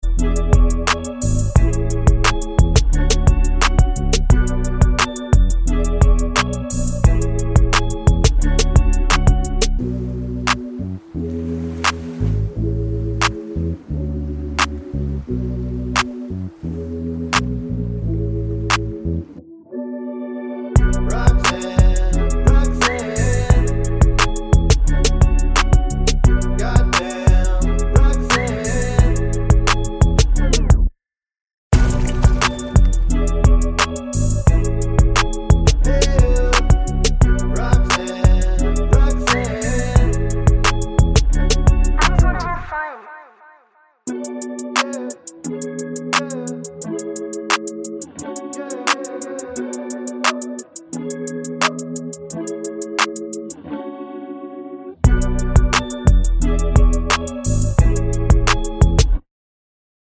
With Censored Backing Vocals Pop (2010s) 2:45 Buy £1.50